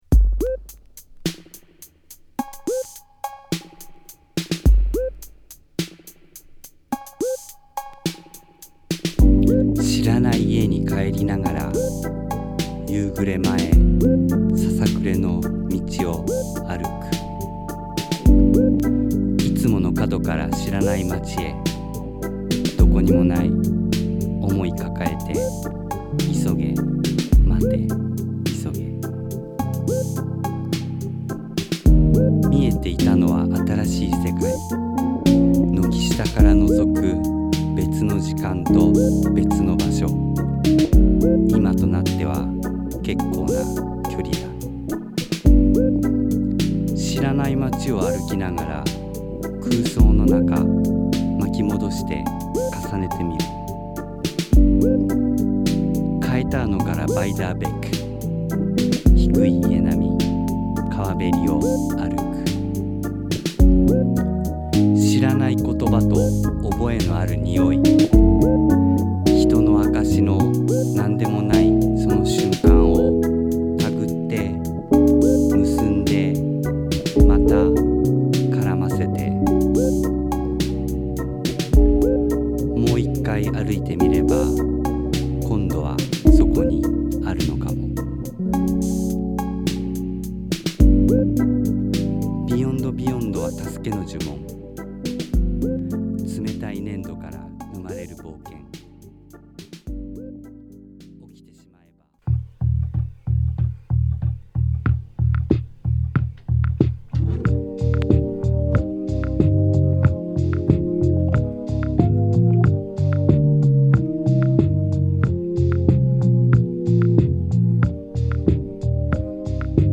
DJユースに 拘った選曲、全曲7インチ用にリマスタリング。
レゲエ/ロックステディとはまた別方向の、和モノグルーヴとしても活躍できそうな一枚。